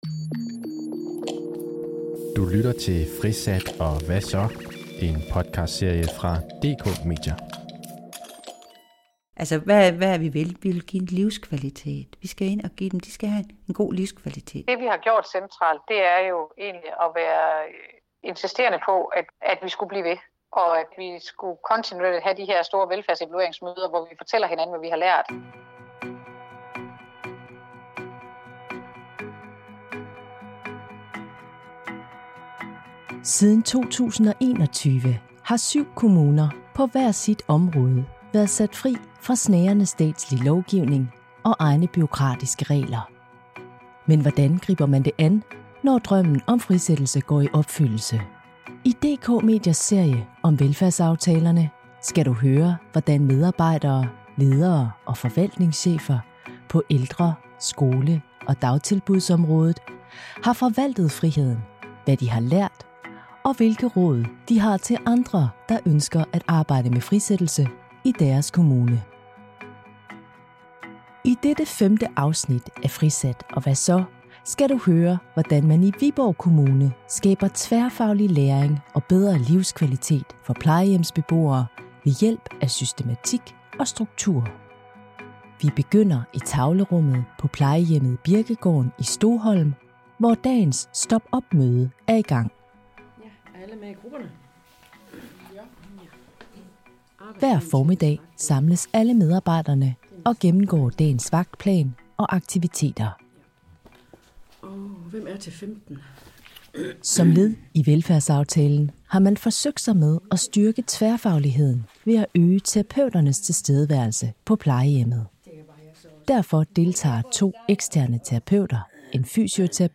I DK Mediers serie om de såkaldte velfærdsaftaler kan du høre, hvordan medarbejdere, ledere og forvaltningschefer på ældre-, skole- og dagtilbudsområdet har forvaltet friheden - hvad de har lært, og hvilke råd de har til andre, der ønsker at arbejde med frisættelse i deres kommune.